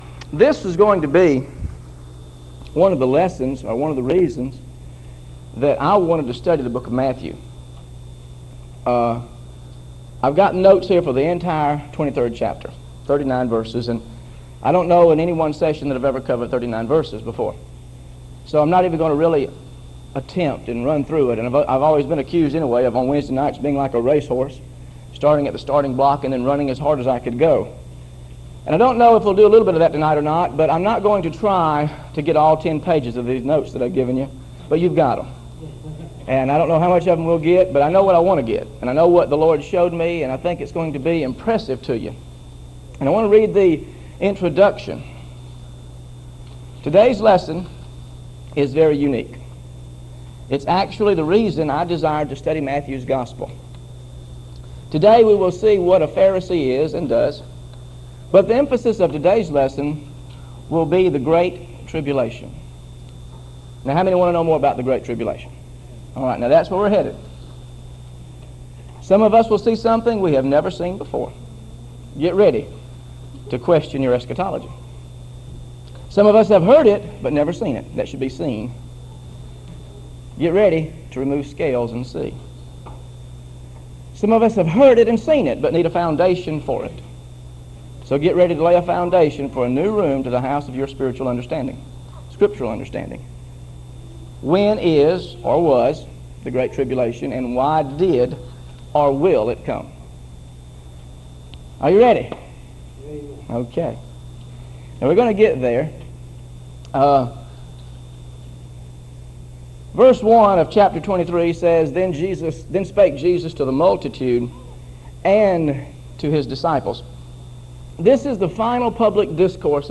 GOSPEL OF MATTHEW BIBLE STUDY SERIES This study of Matthew: Matthew 23: How to Recognize Modern-Day Pharisee Attitudes is part of a verse-by-verse teaching series through the Gospel of Matthew.